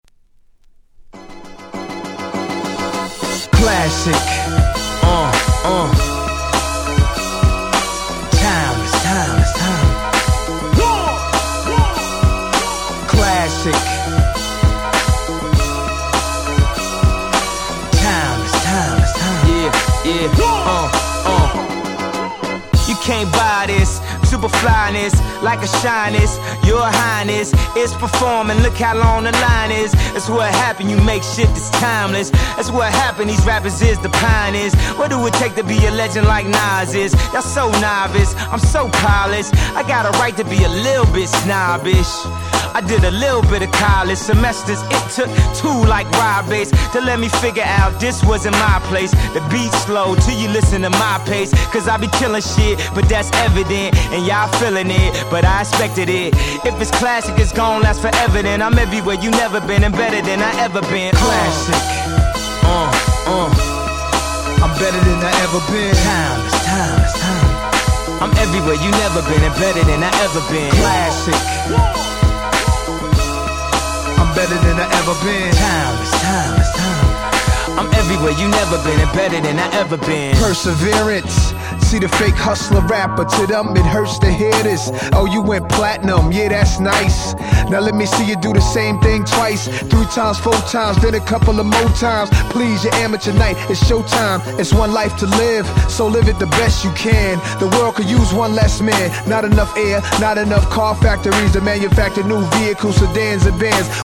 07' Nice Remix !!